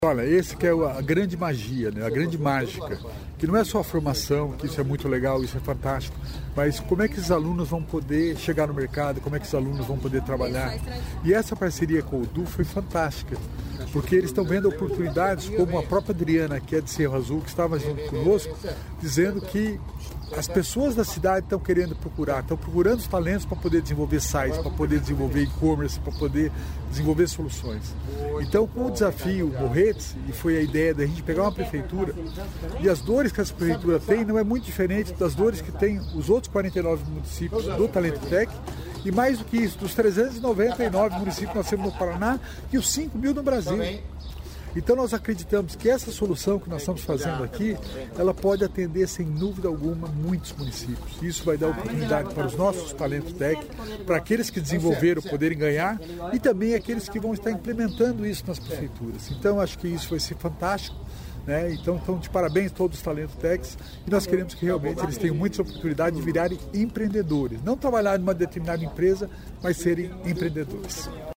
Sonora do secretário da Inovação e Inteligência Artificial, Alex Canziani, sobre as soluções propostas por alunos do Talento Tech para o “Desafio Morretes”